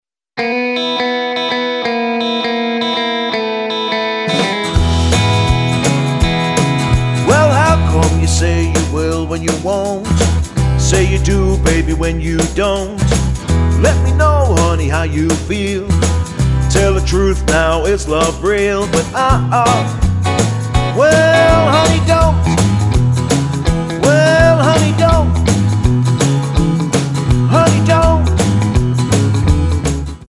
Tonart:E Multifile (kein Sofortdownload.
Die besten Playbacks Instrumentals und Karaoke Versionen .